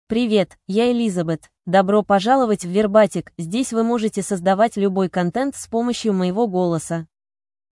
FemaleRussian (Russia)
Voice sample
Female